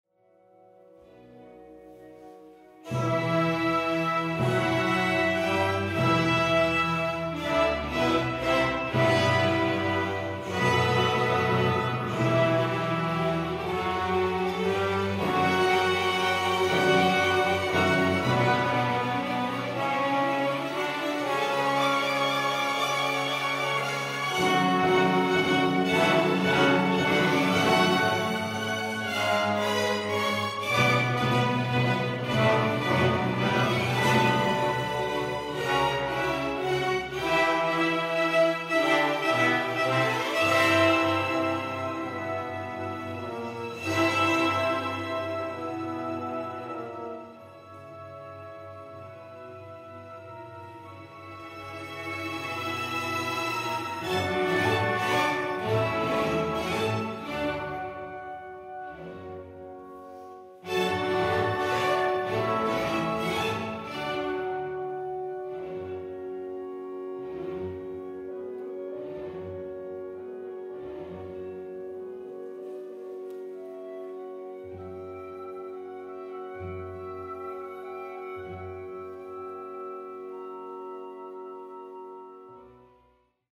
Se trata de la Obertura “Las Bodas de Fígaro” del genial compositor de Salzburgo, Mozart; Extractos de las Suites No.1 y  No. 2 de Peer Gynt del autor noruego Grieg y la Sinfonía No.8 “Inconclusa” del austriaco Schubert, todas obras de repertorio para gran orquesta como la OSY.